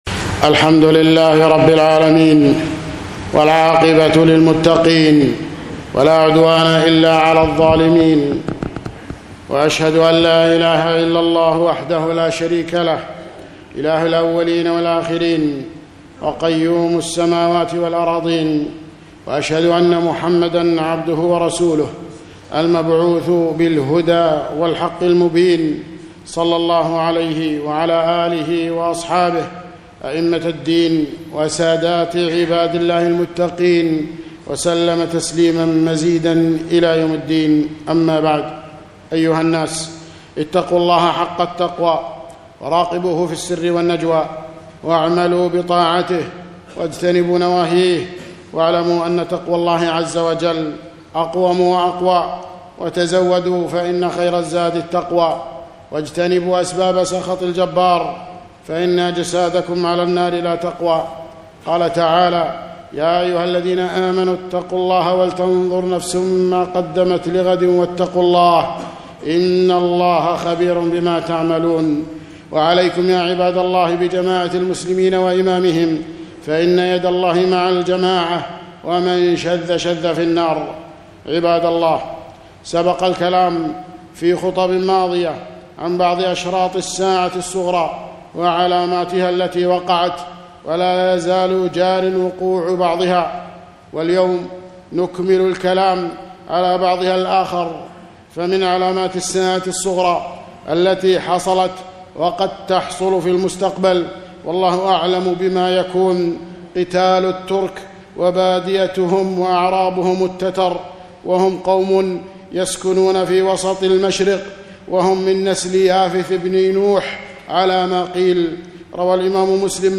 خطبة - من أشراط الساعة